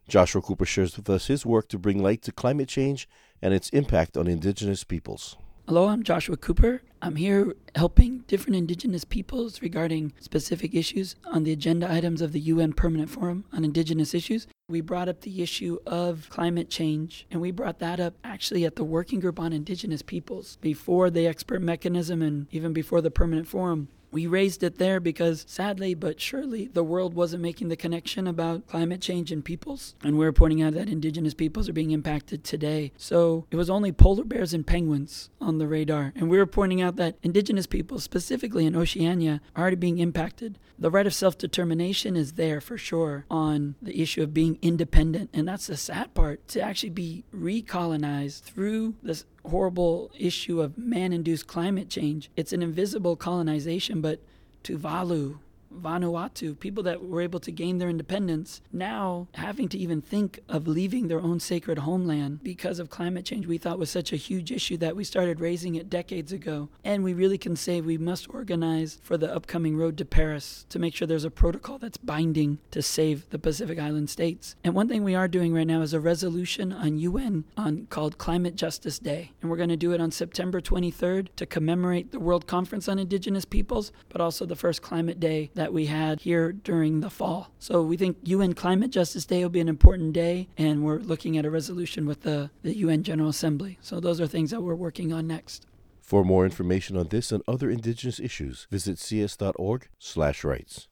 Recording Location: UNPFII 2015
Type: Interview
0kbps Stereo